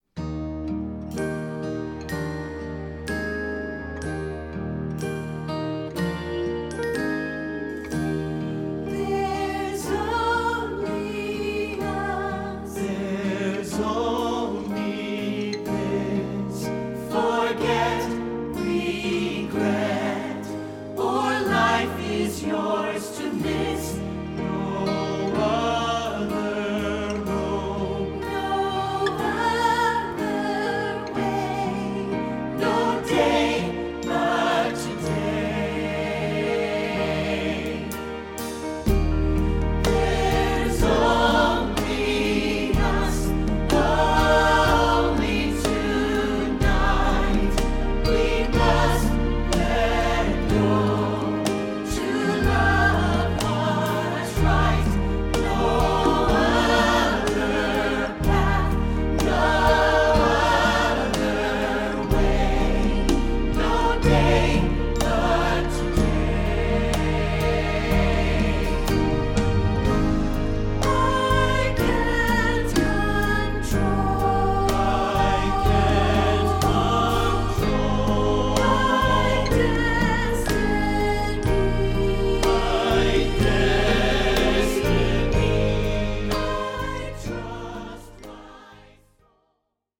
Voicing: SSA